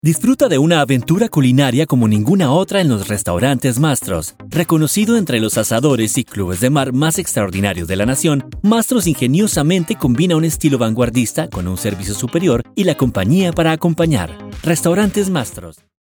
Smooth, Energetic, Professional
Commercial